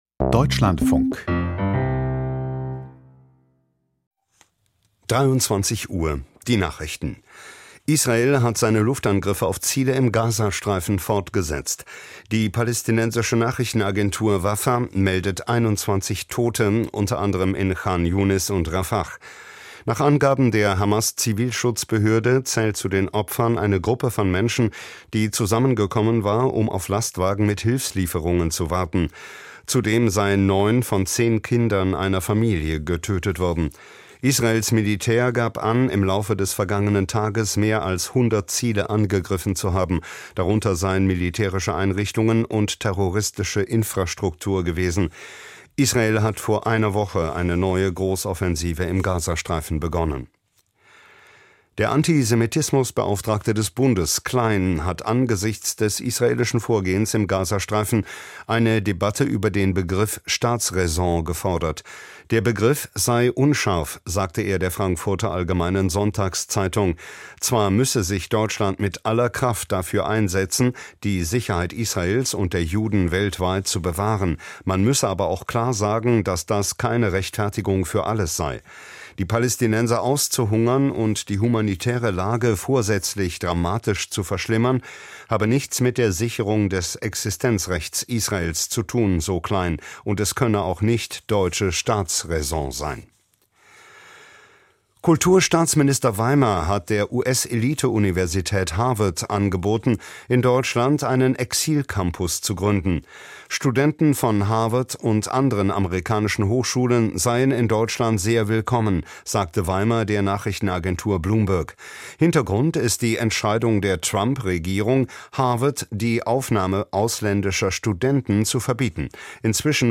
Die Nachrichten vom 24.05.2025, 23:00 Uhr
Die wichtigsten Nachrichten aus Deutschland und der Welt.
Aus der Deutschlandfunk-Nachrichtenredaktion.